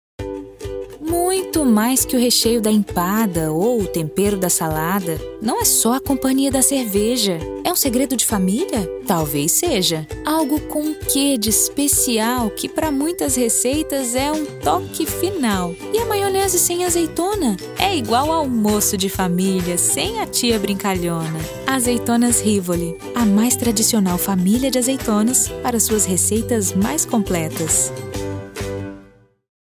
Natural, Reliable, Friendly, Soft, Corporate
E-learning